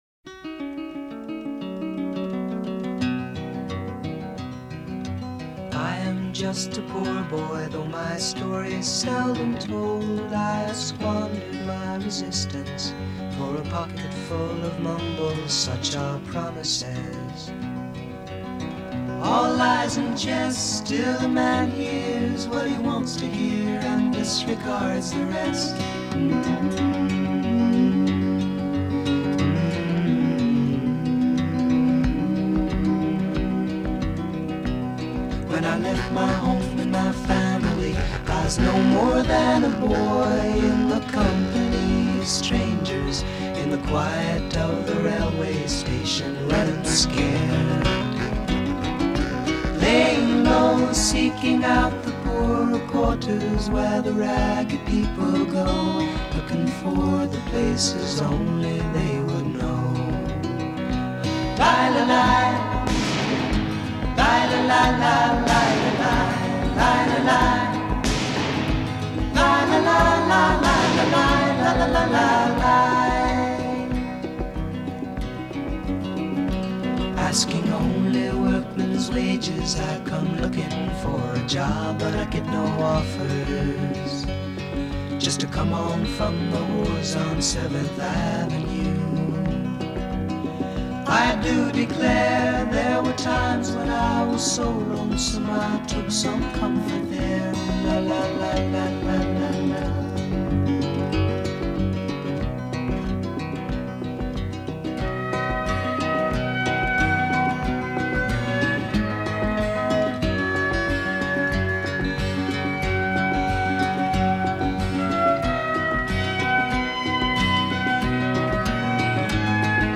Жанр: Folk Rock, Folk, Pop